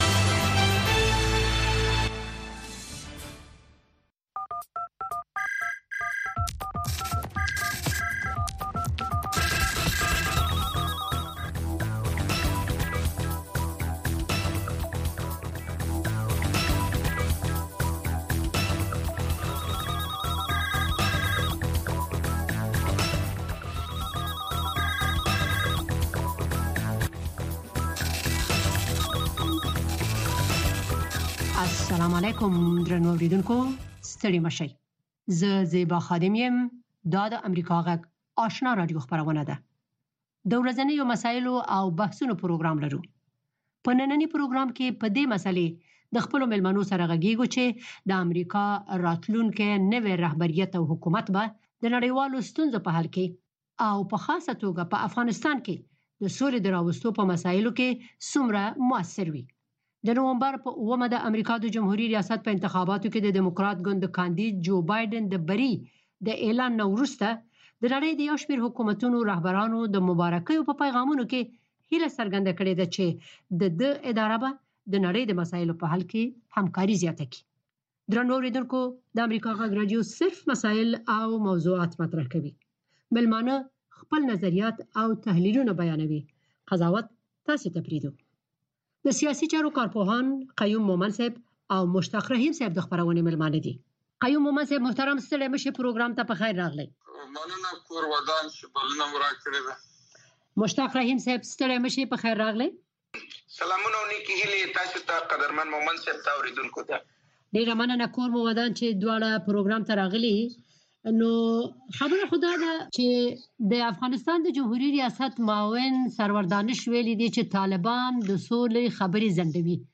خبرې اترې
په دغې خپرونه کې د روانو چارو پر مهمو مسایلو باندې له اوریدونکو او میلمنو سره خبرې کیږي.